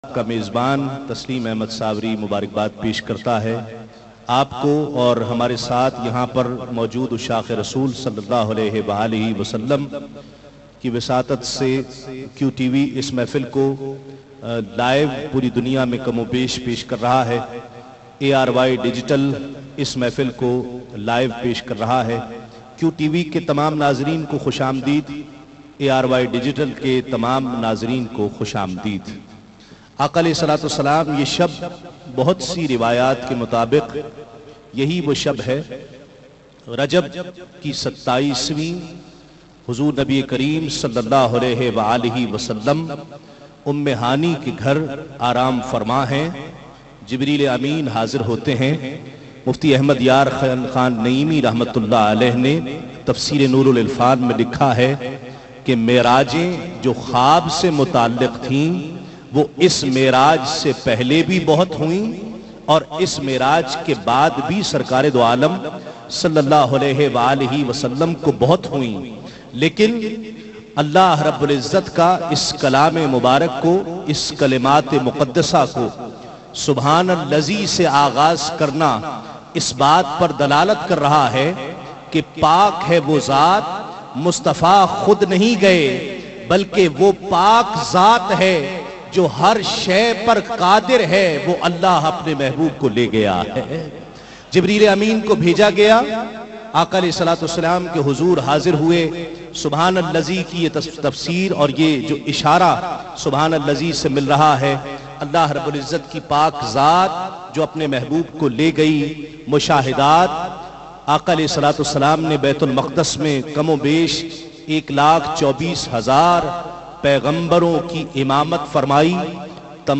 URDU NAAT